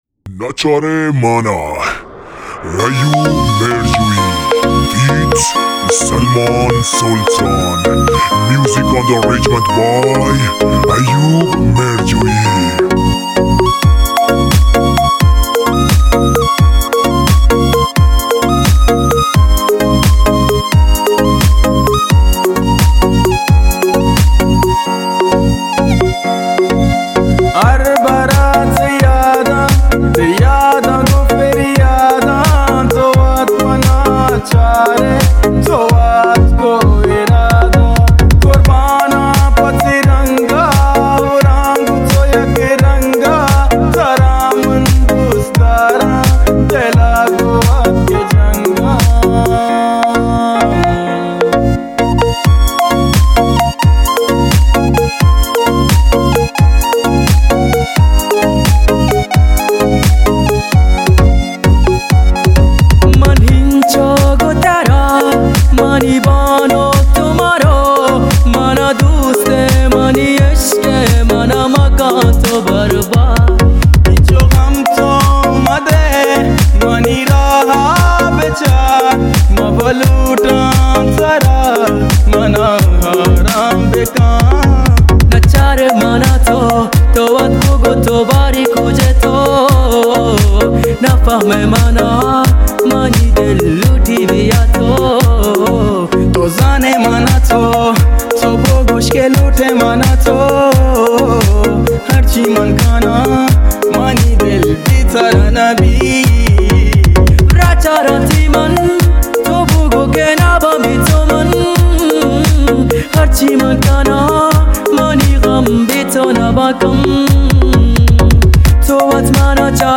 اهنگ بلوچی